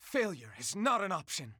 File:Roy voice sample EN.oga
Roy_voice_sample_EN.oga.mp3